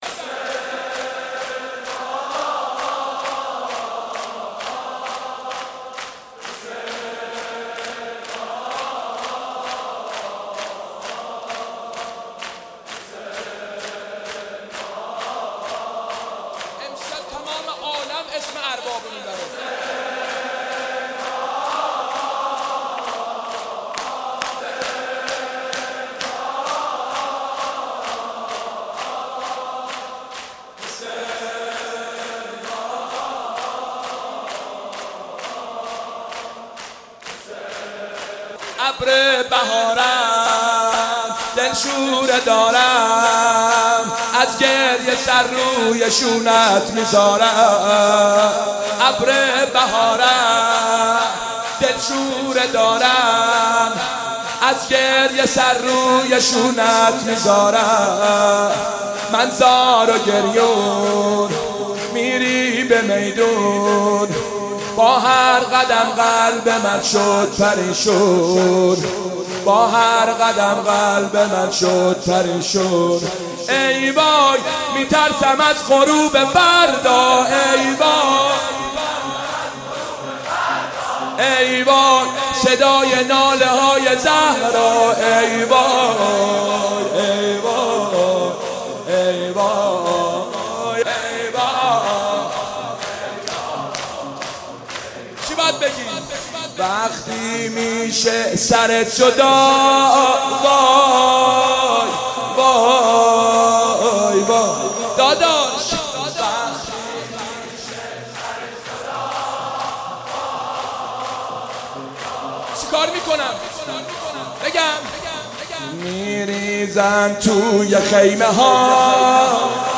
دانود مداحی جدید ابر بهارم دل شوره دارم حسین سیب سرخی _ شور